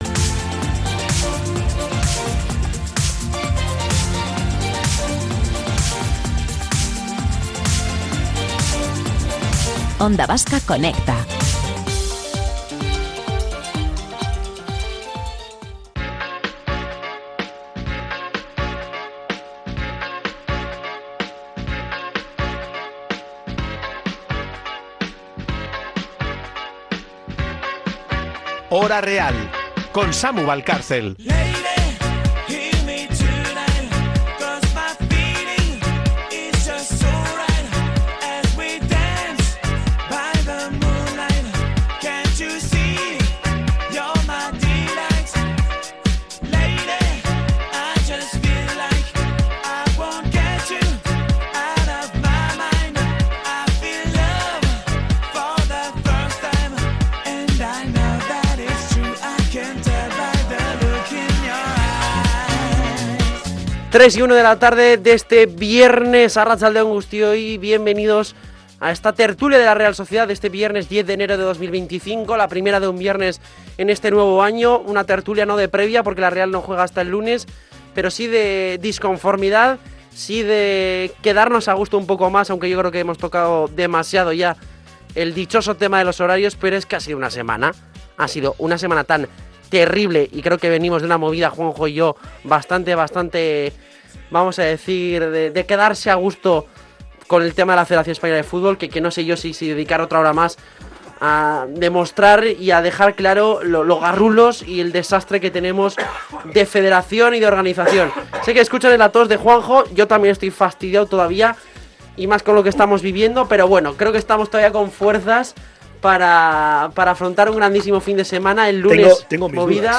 Tertulias